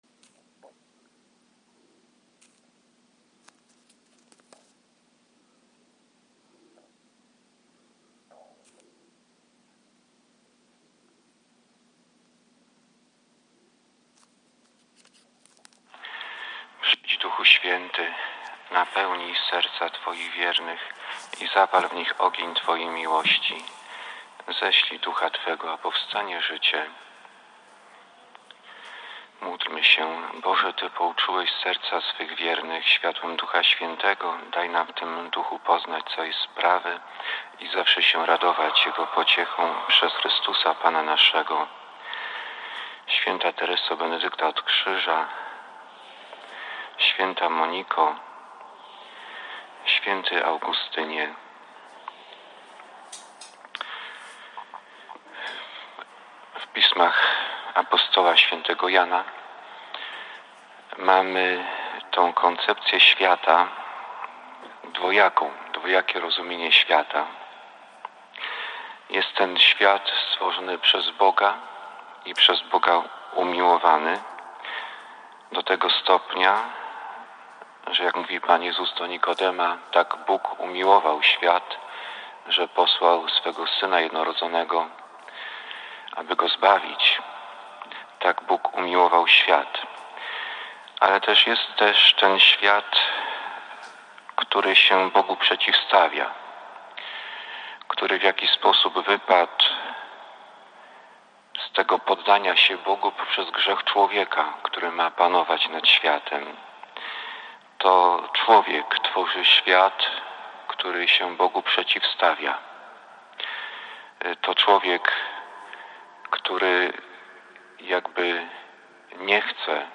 Bazylika �w. J�zefa w Poznaniu